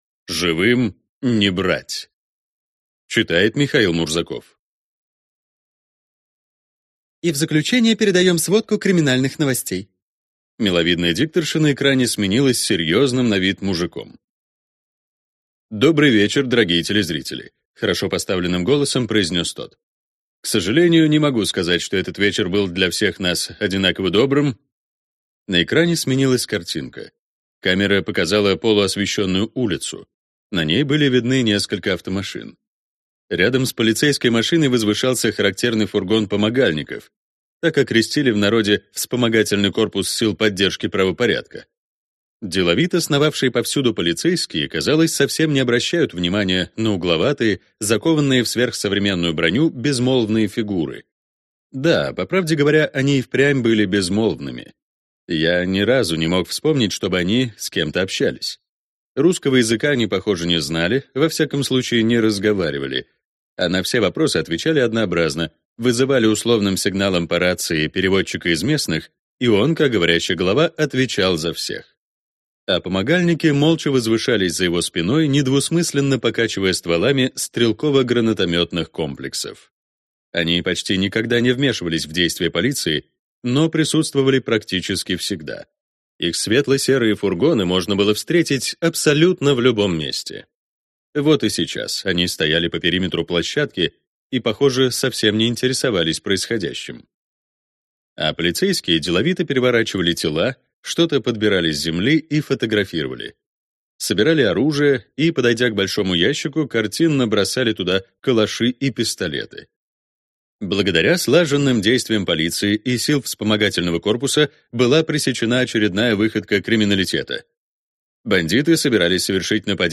Аудиокнига Живым не брать | Библиотека аудиокниг
Прослушать и бесплатно скачать фрагмент аудиокниги